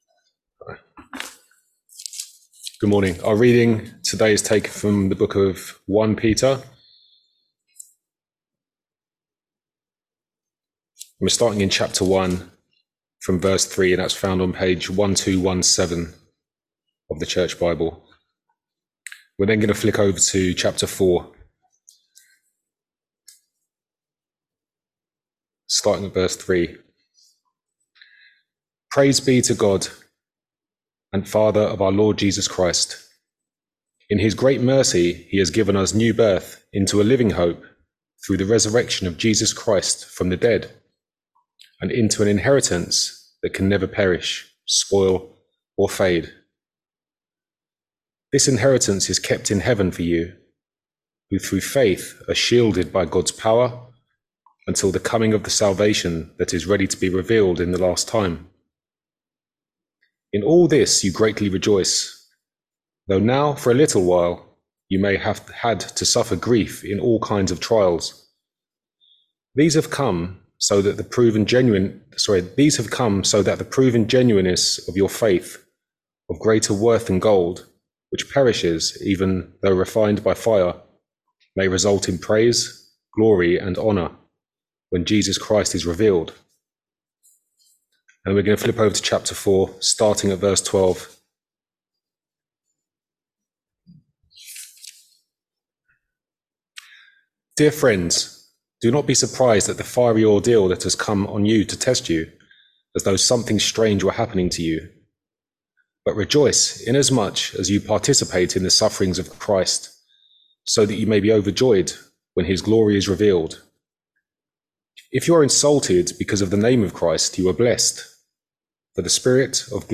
1 Peter 1v3-7;4v12-19 Service Type: Sunday Morning Service Topics